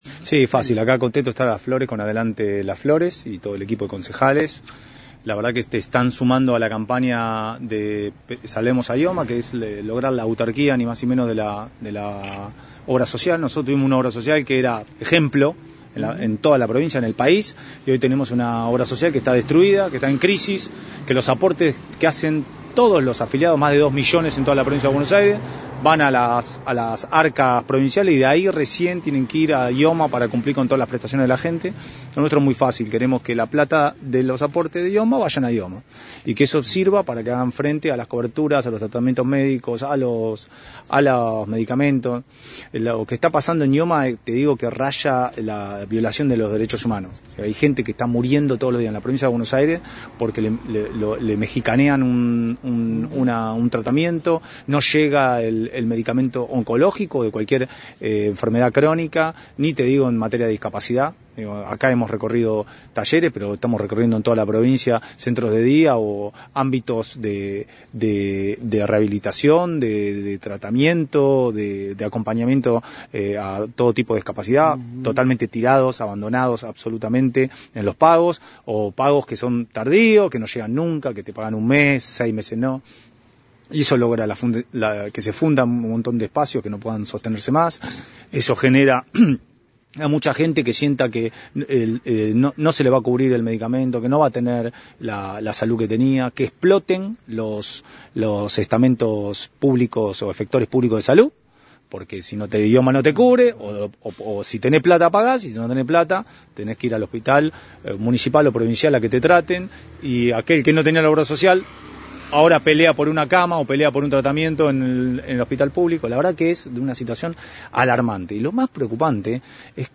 Tal cual lo anunciado el senador bonaerense por la UCR visitó este martes nuestra ciudad en el marco de la campaña «Salvemos a IOMA» impulsada precisamente por el radicalismo para visibilizar el problema de falta de cobertura y atraso en los pagos de la obra social. En diálogo con la 91.5 Bordaisco, acompañado por los concejales del Bloque Adelante Juntos, expresó que «es necesario lograr la autarquía de la obra social.